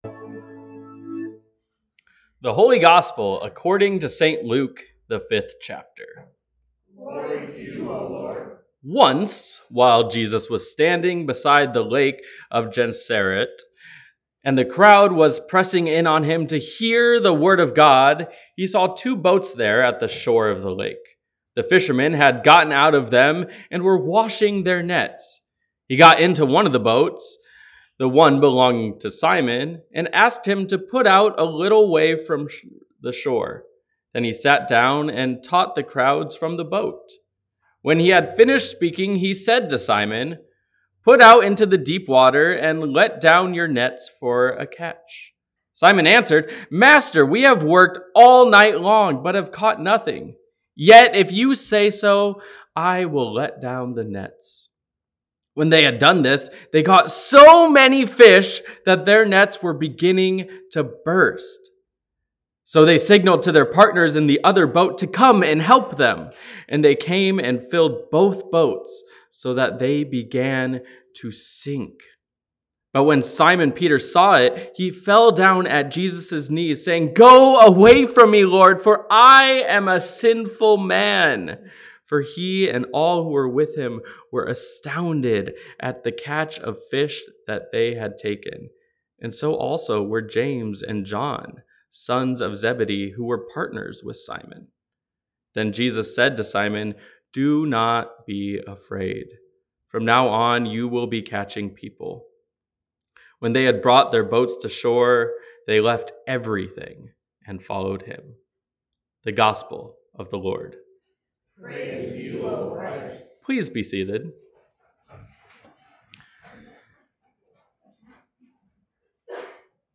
Sermons | Bethany Lutheran Church